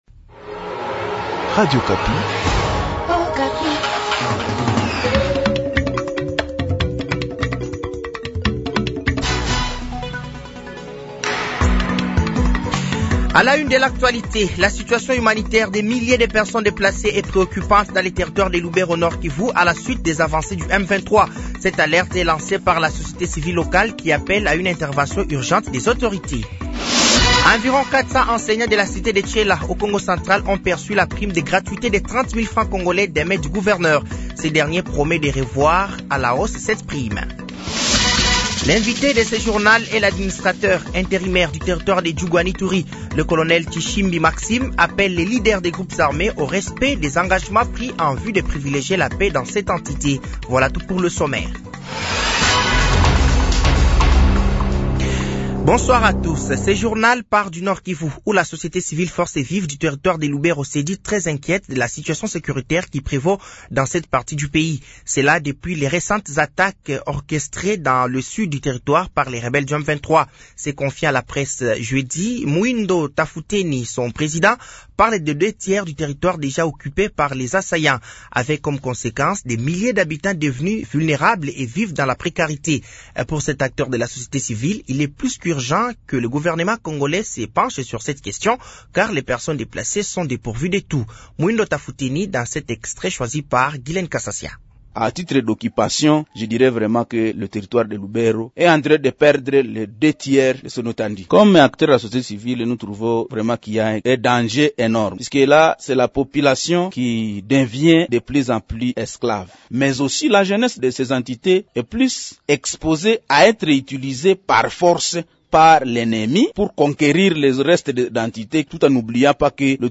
Journal francais de 18h de ce samedi 21 decembre 2024